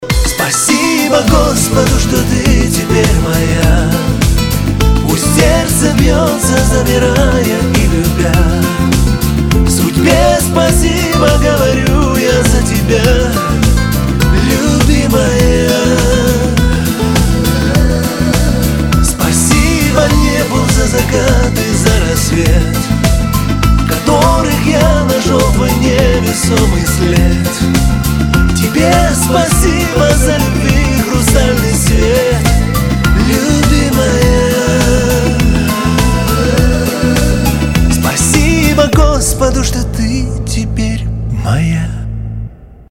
577 Категория: Нарезки шансона Загрузил